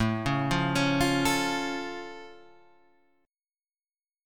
A+ chord {5 4 3 6 6 5} chord